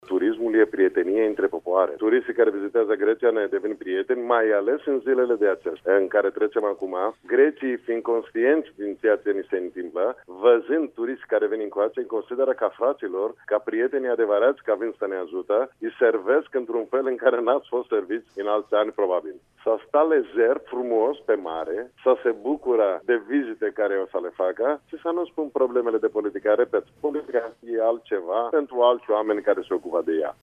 Invitat la Radio România Actualități